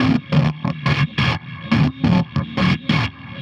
tx_perc_140_supercrunch.wav